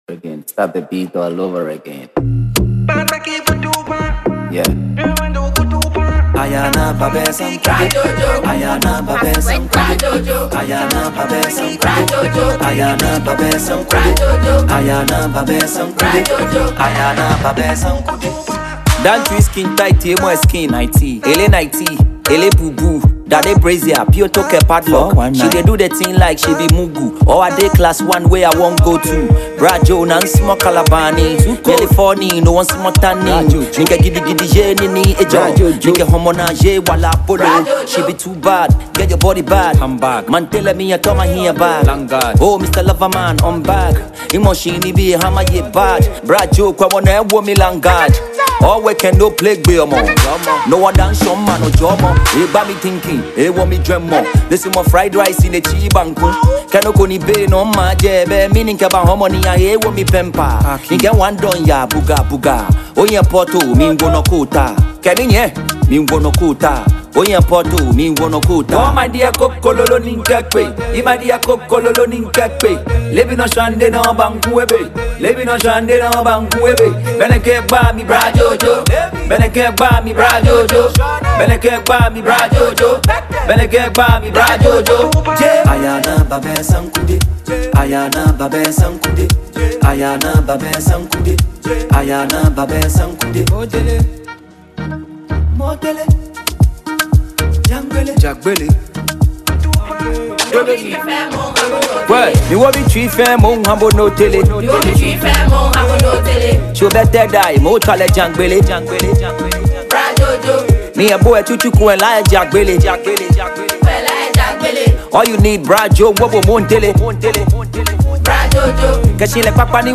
a legend in the Ghana hiplife genre